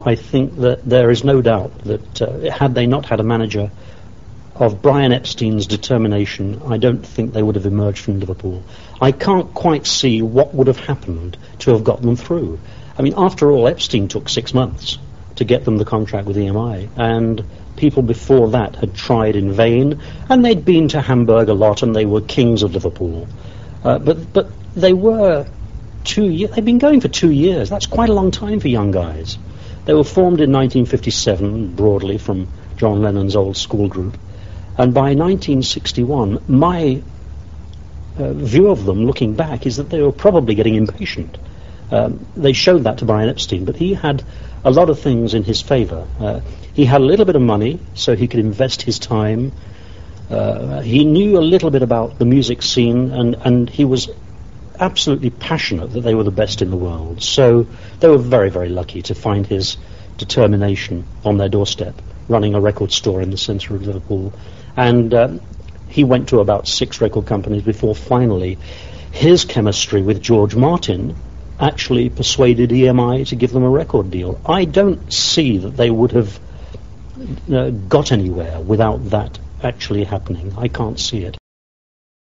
Here are a few never before seen extracts from those interviews: